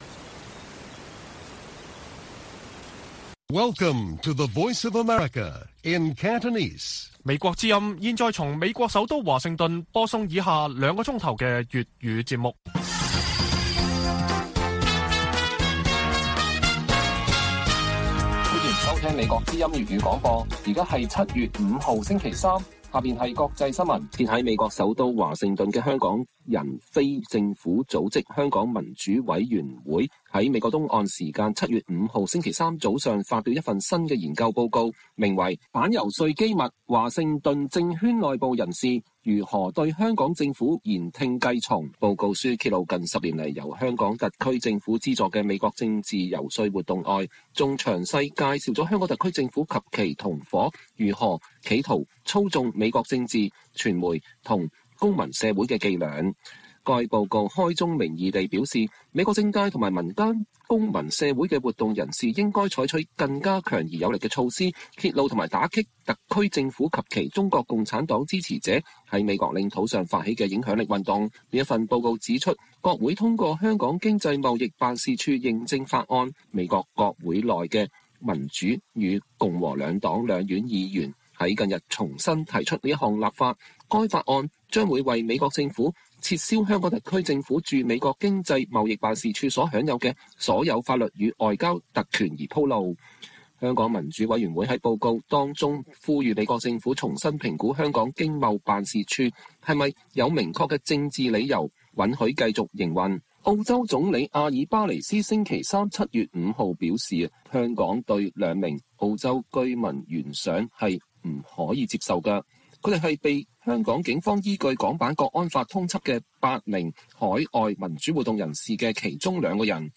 粵語新聞 晚上9-10點 香港民主委員會報告揭露香港政府在美政治游説活動